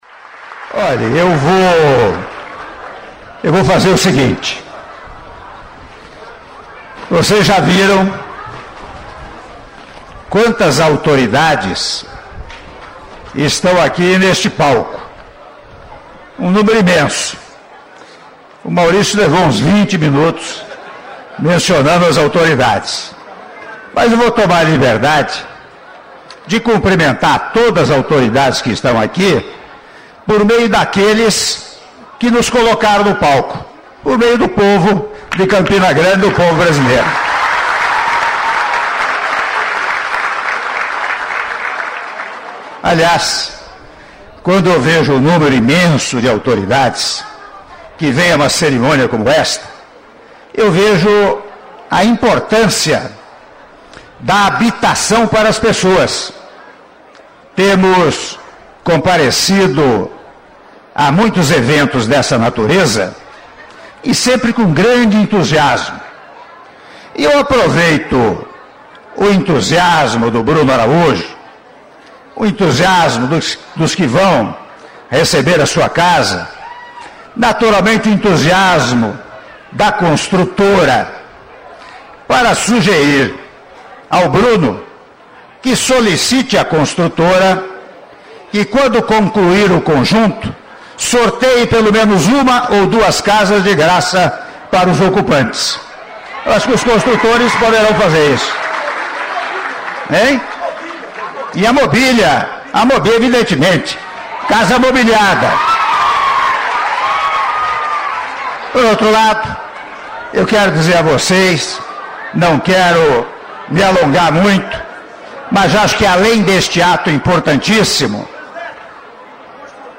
Áudio do discurso do presidente da República, Michel Temer, durante Cerimônia de assinatura de ordem de serviço para adequação de capacidade da BR-230 – Trecho Cabedelo - Oitizeiro -Trecho Cabedelo - Oitizeiro/PB (07min46s)